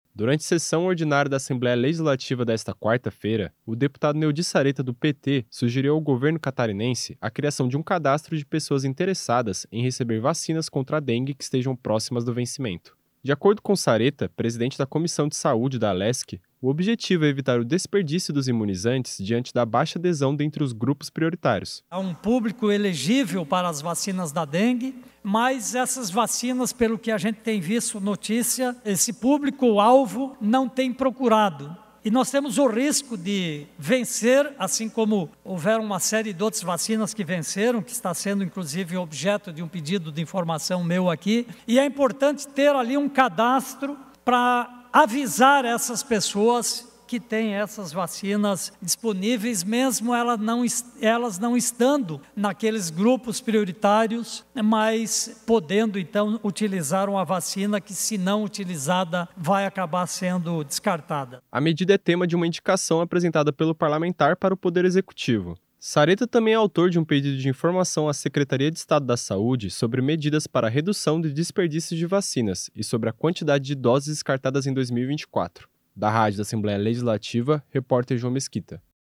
Entrevista com:
- deputado Neodi Saretta (PT), presidente da Comissão de Saúde.